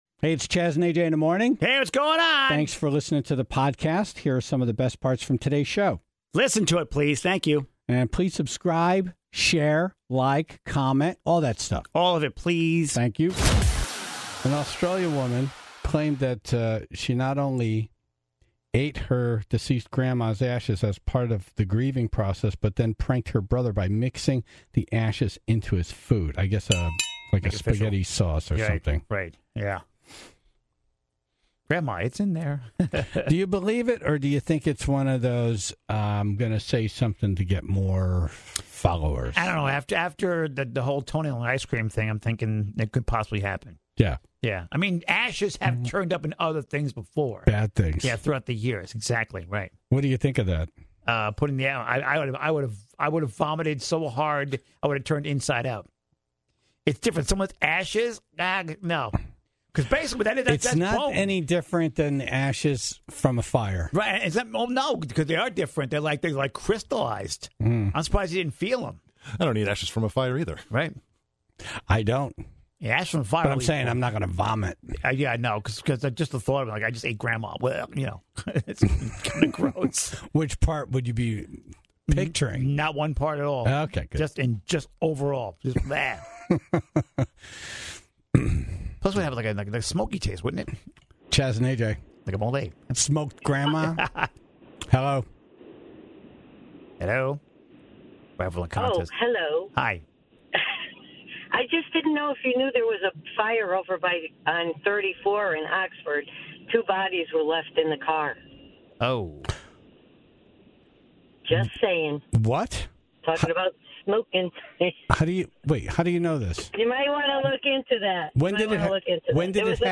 Paranormal expert
in-studio to talk about CT's legendary Melonheads, haunted dolls and native burial grounds.